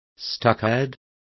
Also find out how vallado is pronounced correctly.